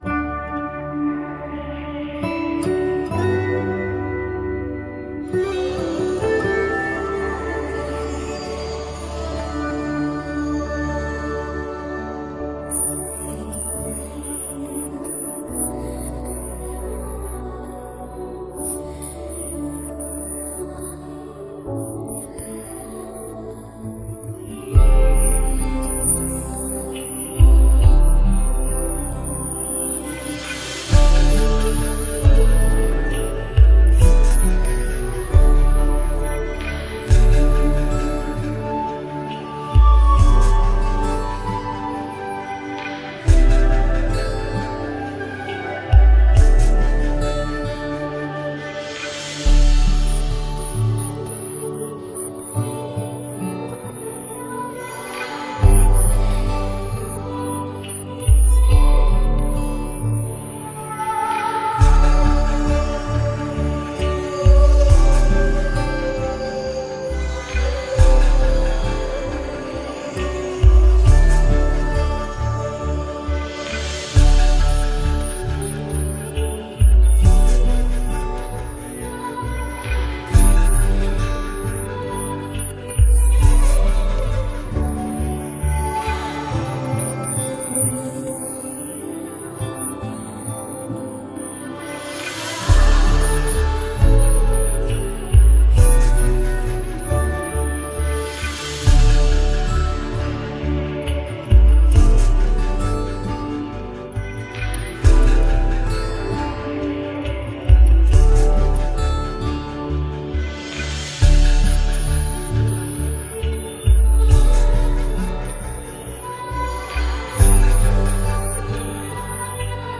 Play Karaoke & Sing with Us